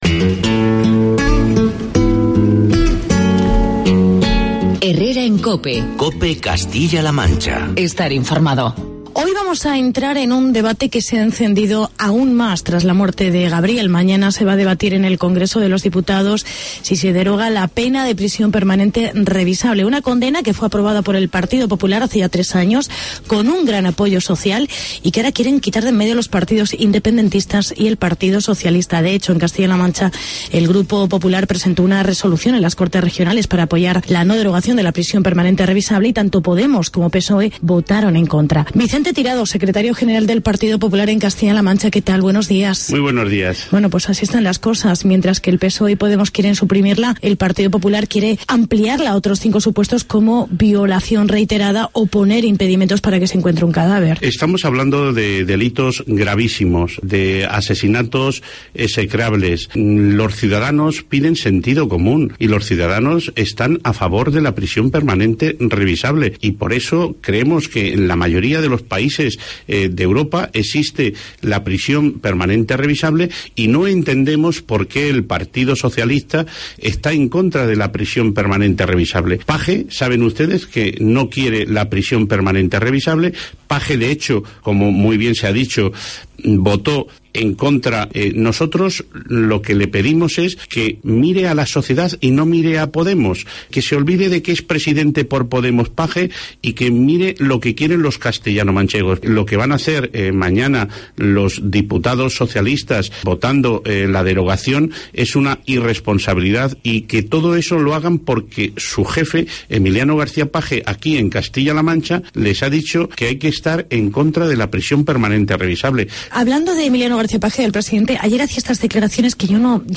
Entrevista con Vicente Tirado. Secretario General PP CLM